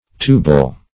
Tubal \Tub"al\, a.